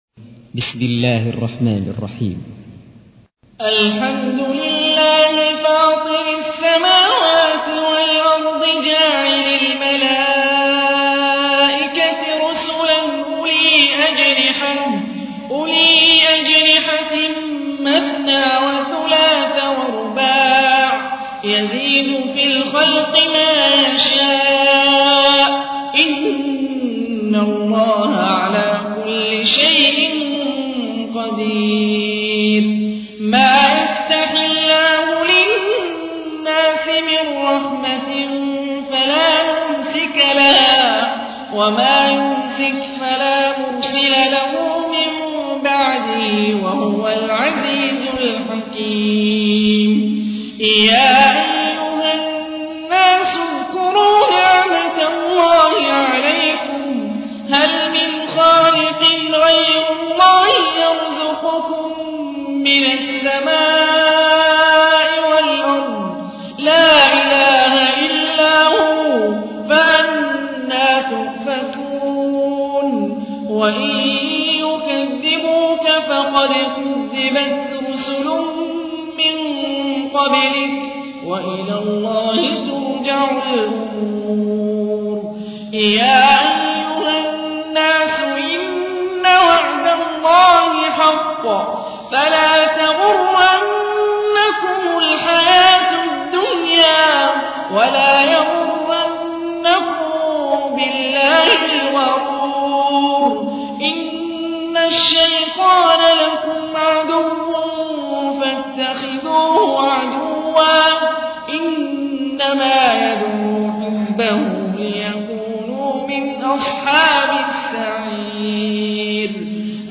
تحميل : 35. سورة فاطر / القارئ هاني الرفاعي / القرآن الكريم / موقع يا حسين